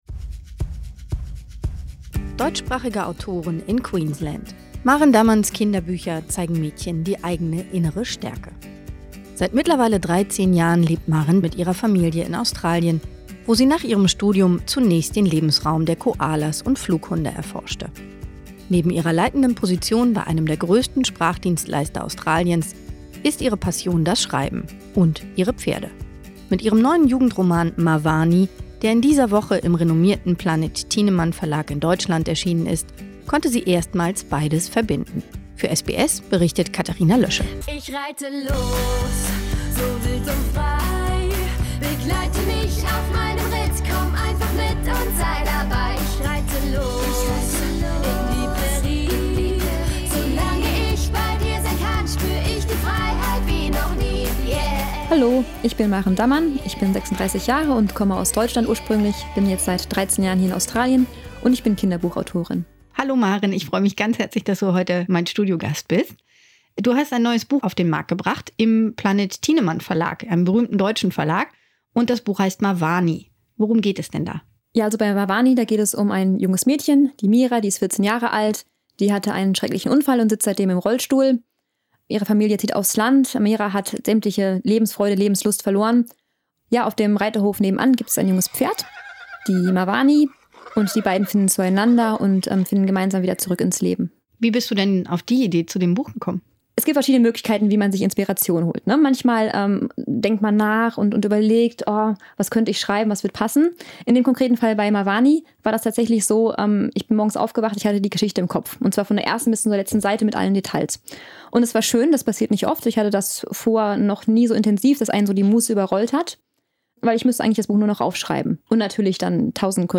Radio Interview über das Buch, seine Entstehung und Hintergründe.